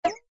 MG_maze_pickup.ogg